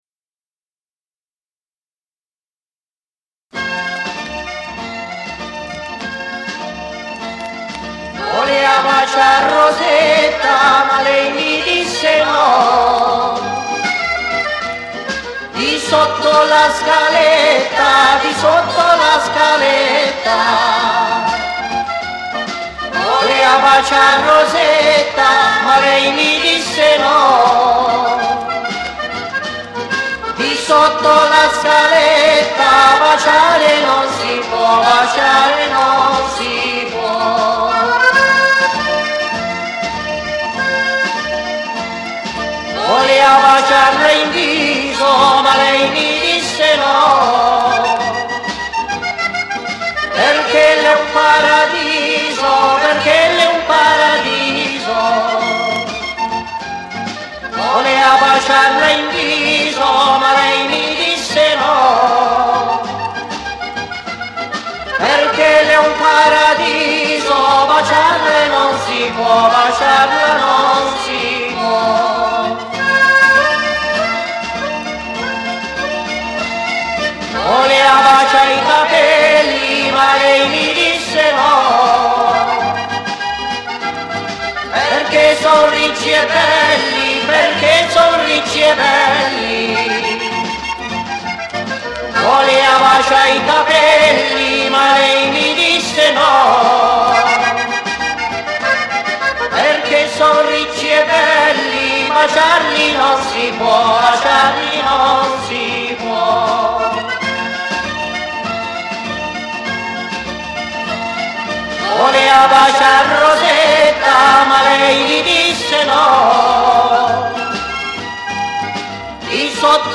CANTO POPOLARE